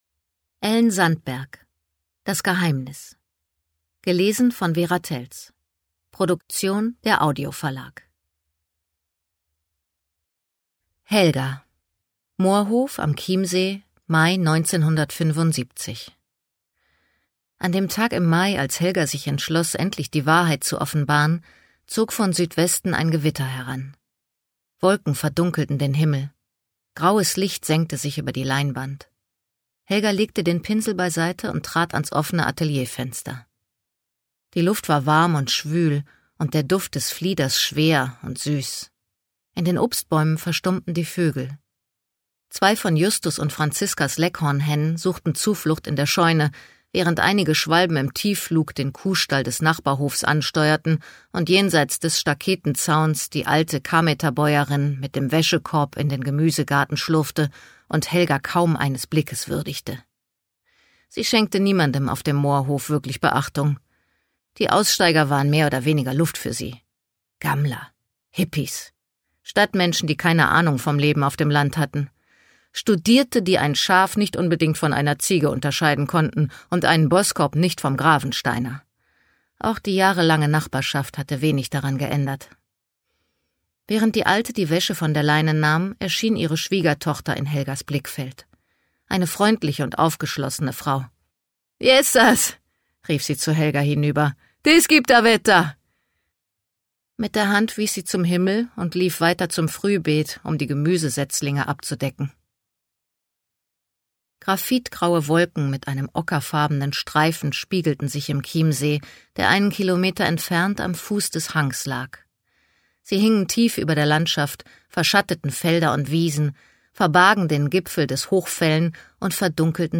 Ungekürzt